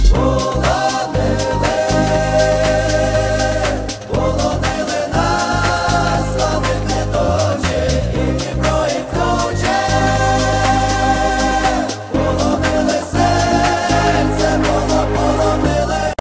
• Качество: 321 kbps, Stereo